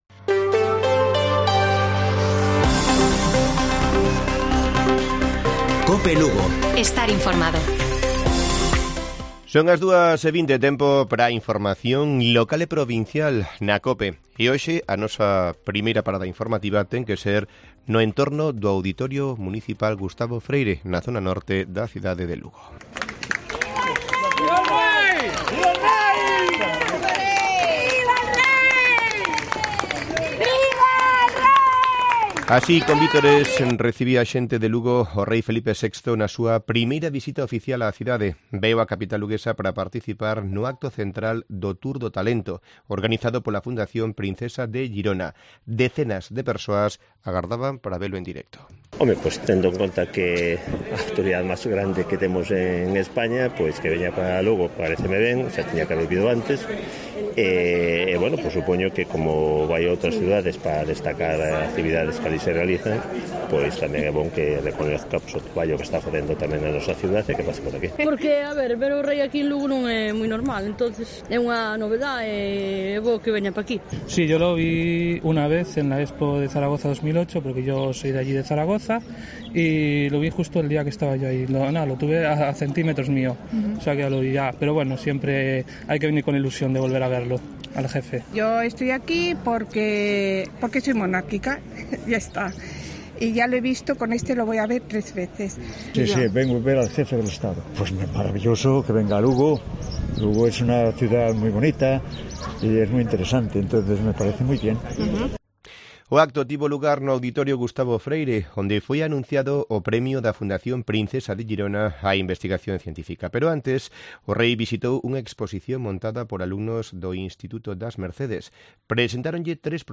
Informativo Mediodía de Cope Lugo. 5 de maio. 14:20 horas